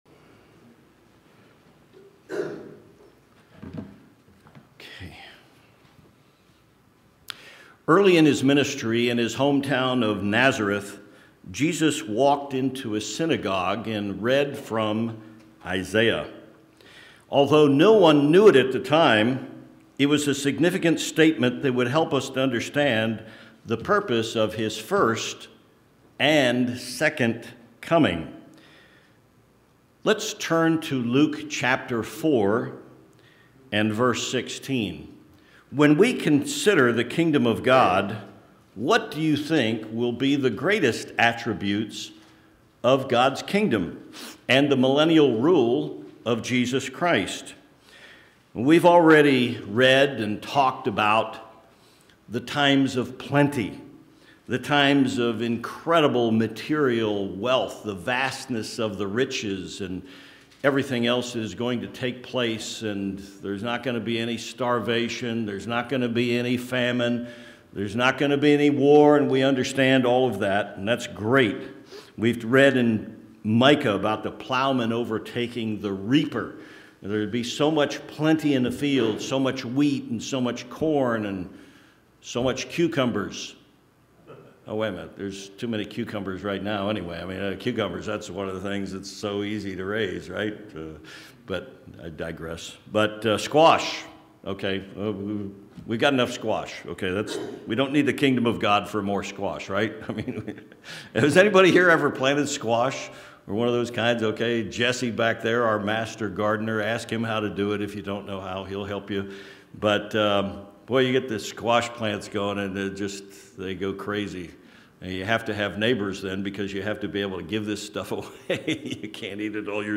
Sermons
Given in El Paso, TX Tucson, AZ